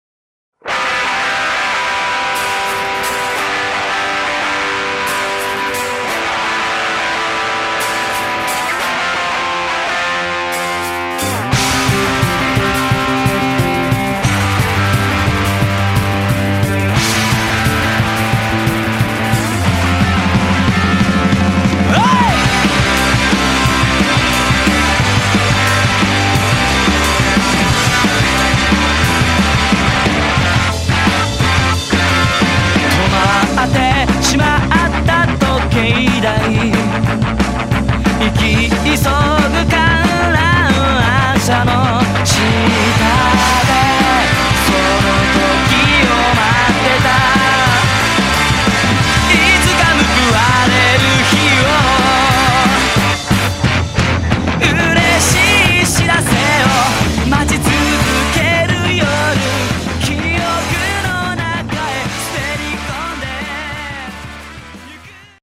キャッチーなメロディーで定評ある直球に、より「伝わる」力をスケールアップさせた力作。